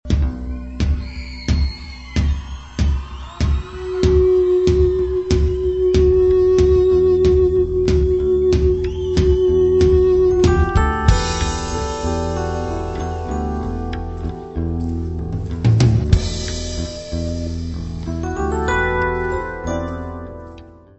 baixo, voz
guitarra, teclas, voz.
Music Category/Genre:  Pop / Rock